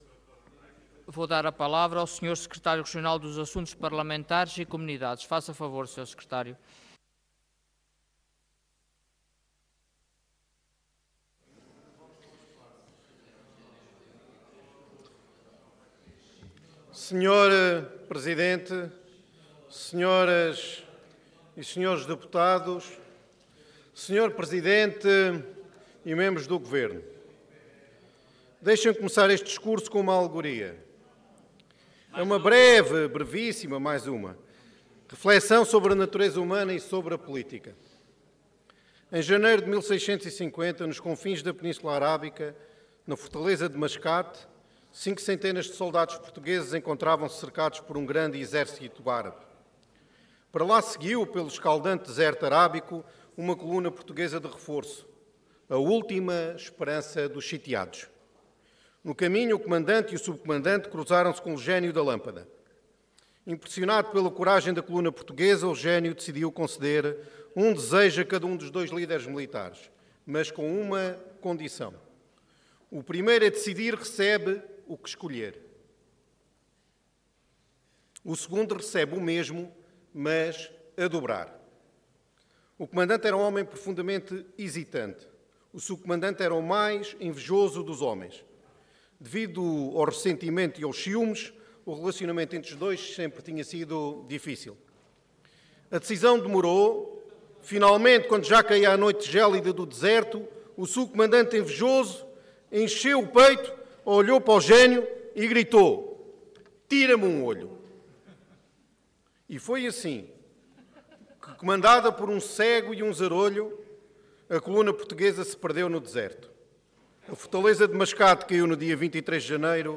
Intervenção de Tribuna Orador Paulo Estêvão Cargo Secretário Regional dos Assuntos Parlamentares e Comunidades